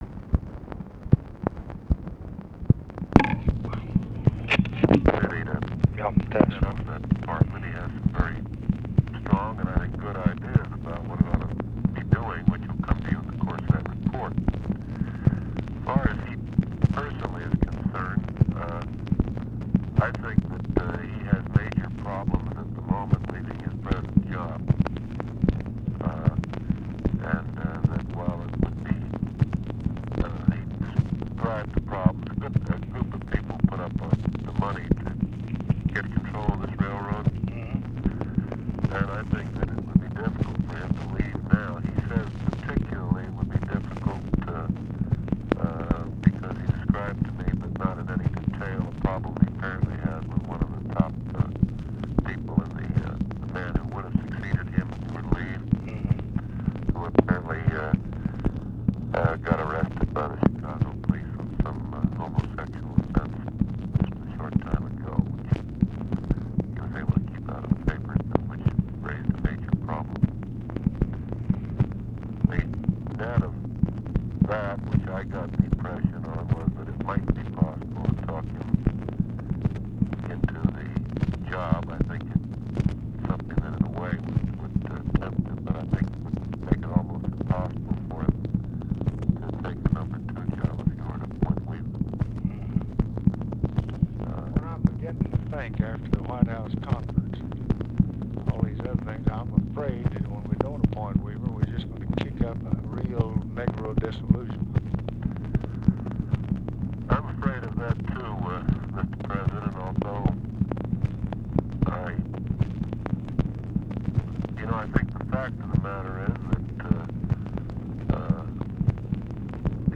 Conversation with NICHOLAS KATZENBACH, November 29, 1965
Secret White House Tapes